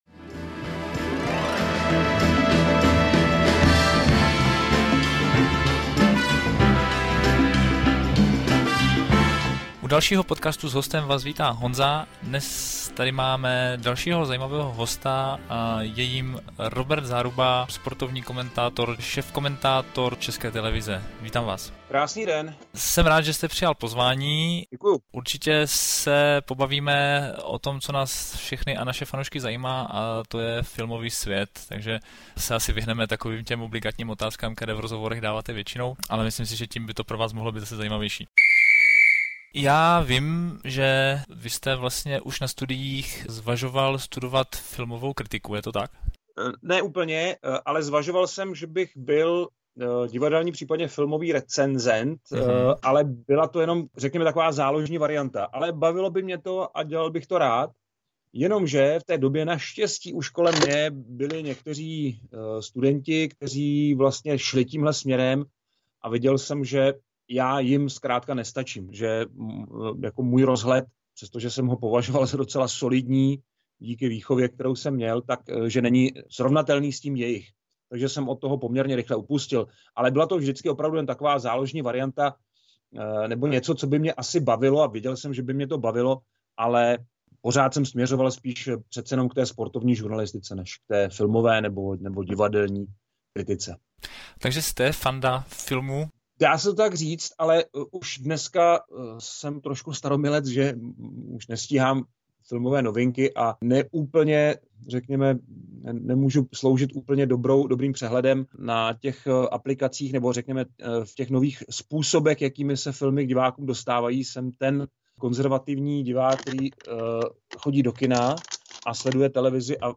Jsem moc rád, že pozvání k rozhovoru přijal sportovní komentátor české televize Robert Záruba.